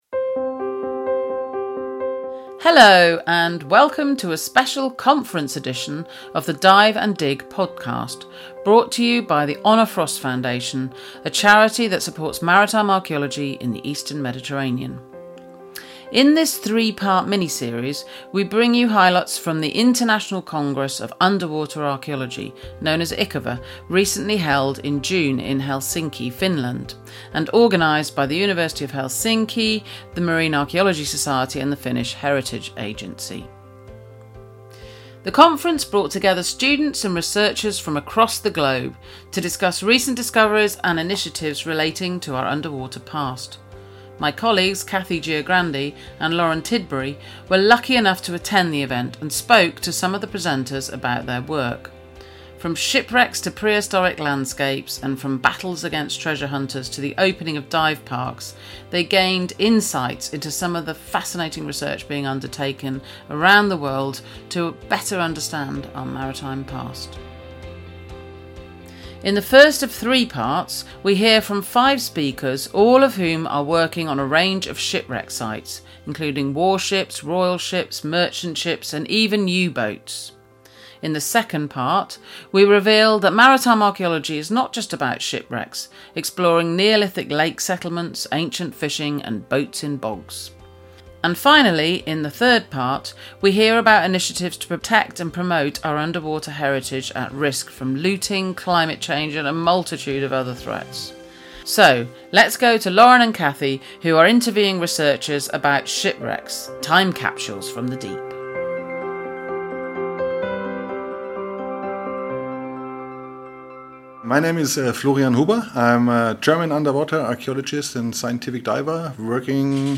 Part I of the special IKUWA conference edition of our podcast looks at Shipwrecks - Timecapsules from the Deep! We hear from five speakers all of whom are working on a range of shipwreck sites, including warships, royal ships, merchant ships and even U-Boats.